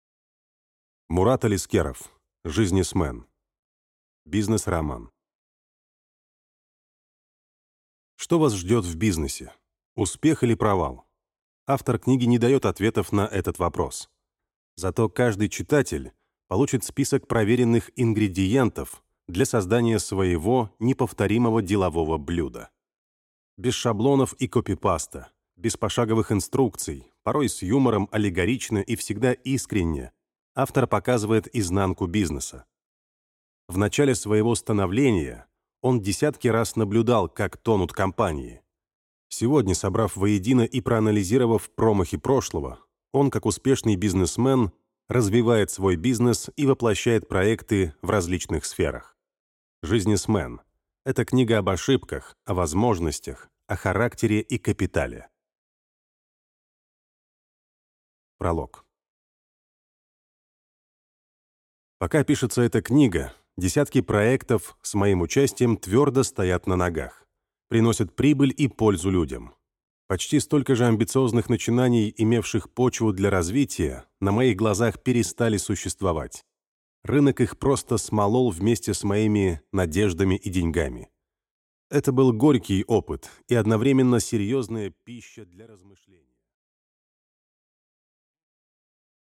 Аудиокнига Жизнесмен. Принципы как путь к вашему капиталу | Библиотека аудиокниг